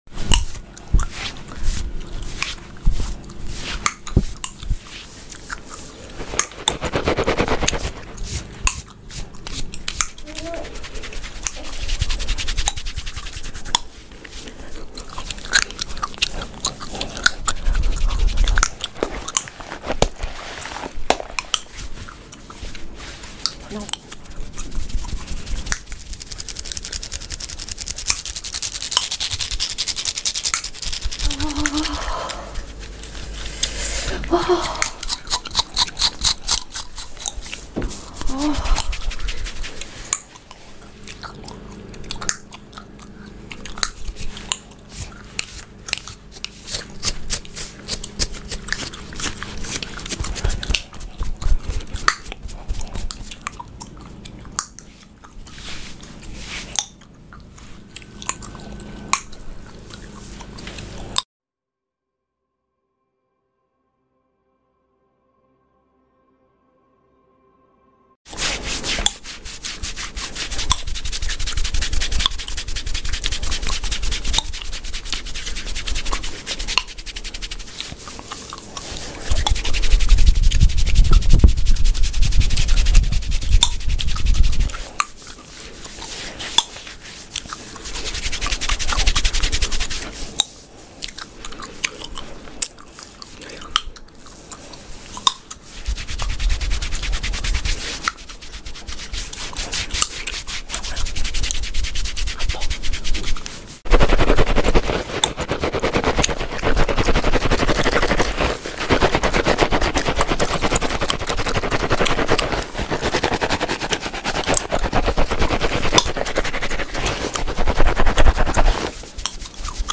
ASMR ON MY BLONDIE WITH sound effects free download
ASMR ON MY BLONDIE WITH GUM CRACKING SOUND.